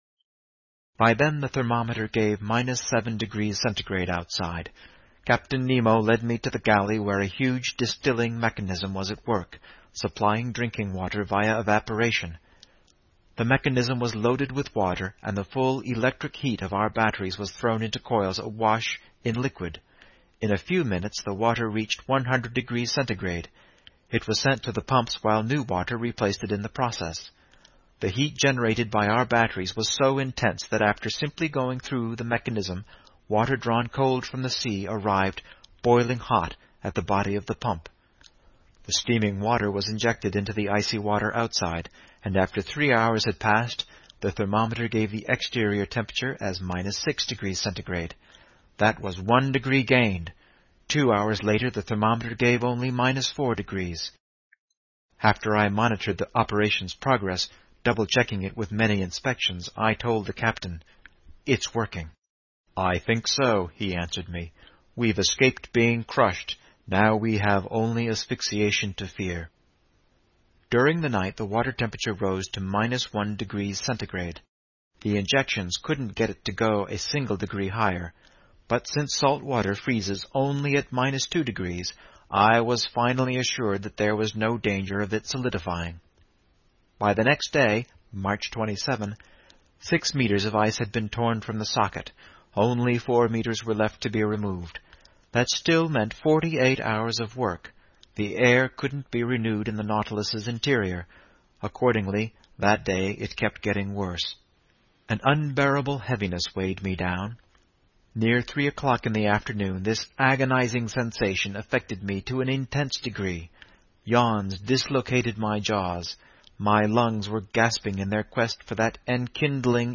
在线英语听力室英语听书《海底两万里》第474期 第29章 缺少空气(6)的听力文件下载,《海底两万里》中英双语有声读物附MP3下载